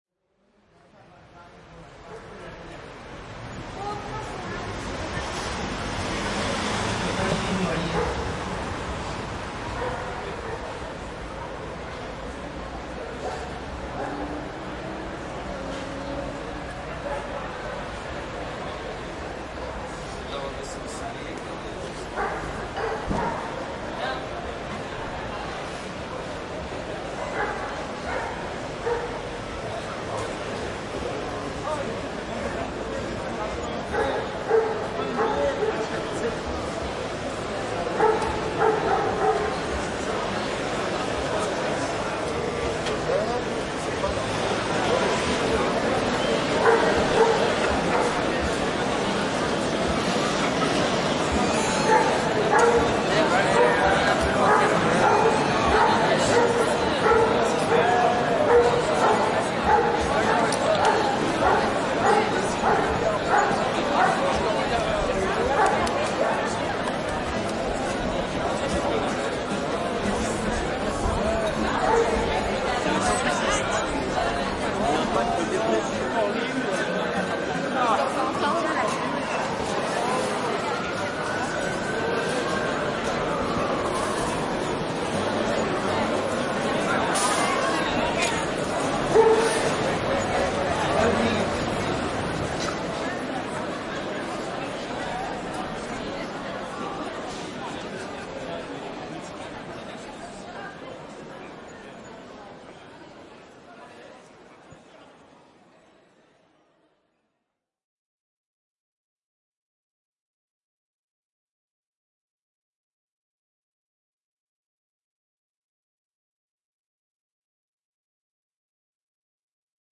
描述：缓慢而深刻的科幻氛围配乐。无缝循环，每分钟108次。
标签： 处理时 背景声 声景 氛围 合成器 ATMO ATMOS 黑暗 电影 反物质 空间 科幻 气氛 音带
声道立体声